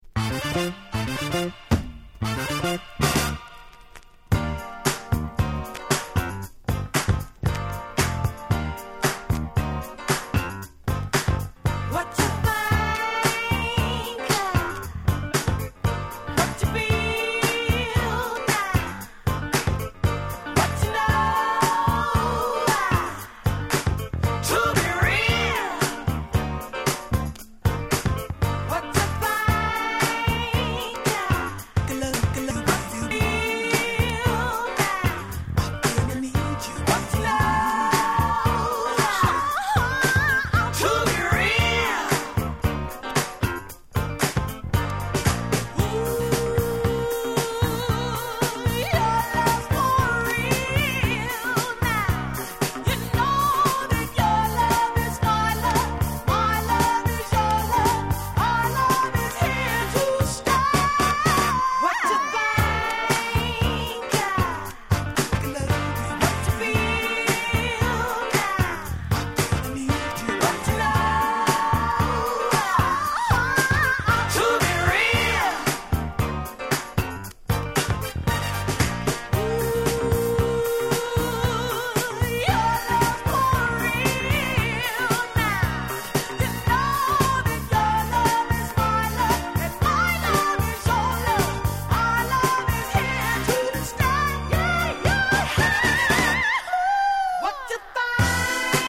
78' Super Hit Dance Classic !!